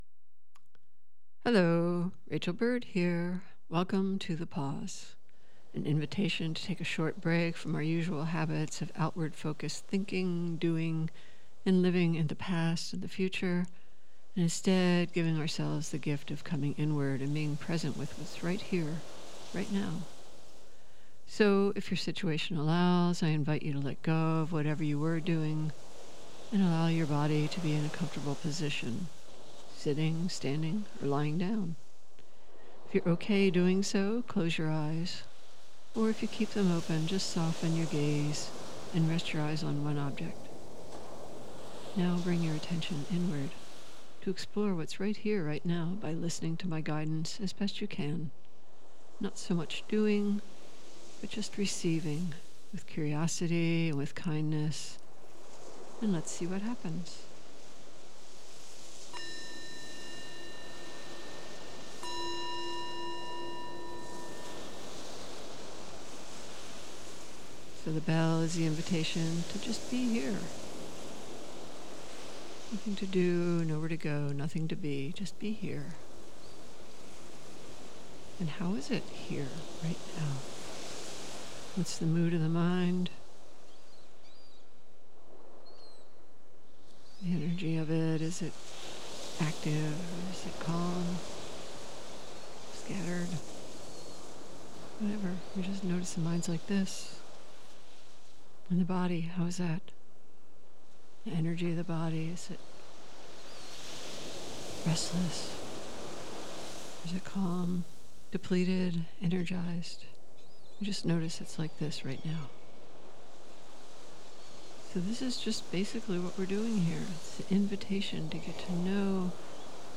Meditation: inviting curiosity about direct experience in the present moment.